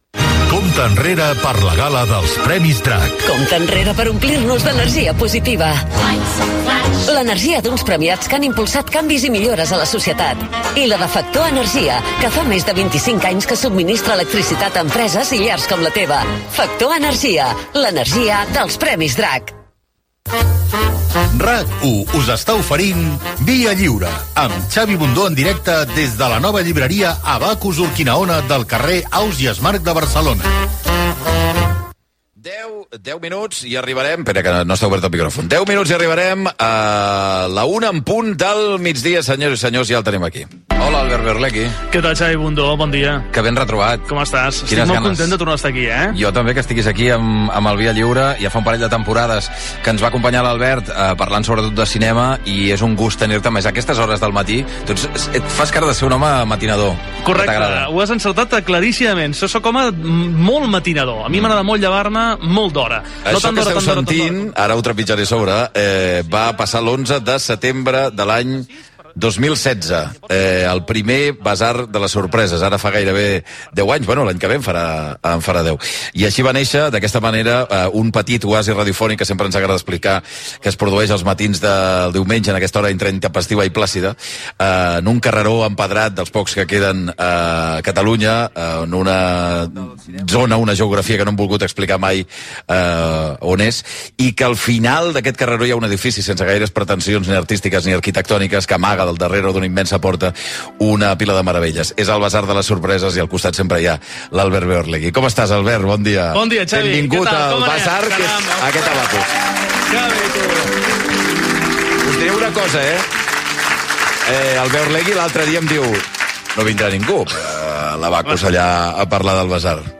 3b48fd665de43d921009d1559a6b5c4e9b861d45.mp3 Títol RAC 1 Emissora RAC 1 Barcelona Cadena RAC Titularitat Privada nacional Nom programa Via lliure Descripció Programa fet des de la llibreria Abacus del carrer Ausiàs Marc de Barcelona.